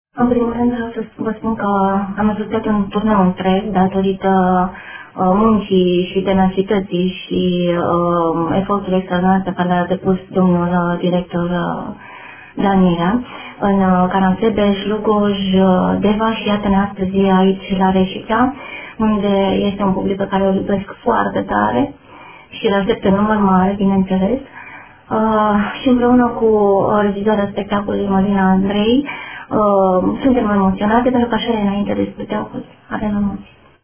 Acest recital a încercat să-i culeagă visele, să le pună-n căuşul sufletului nostru pentru a-i ostoii dorul de această lume.